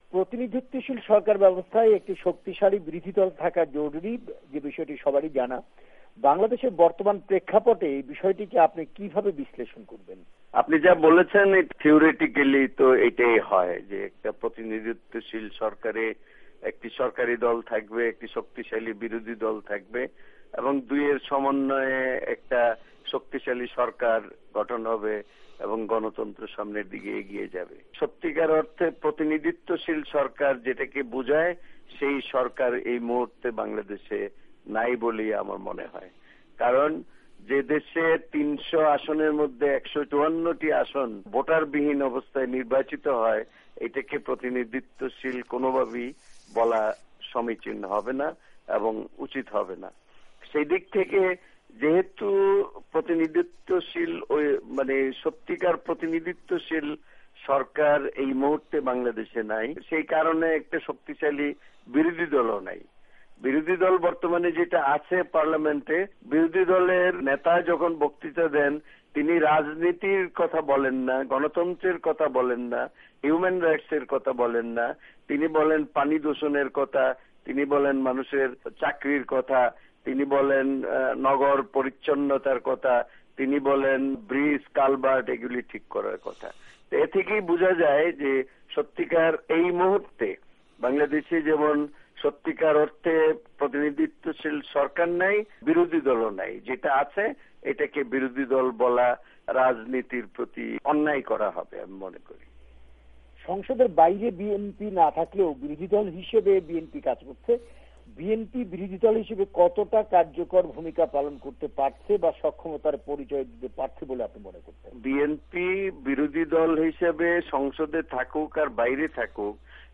সাক্ষাত্কার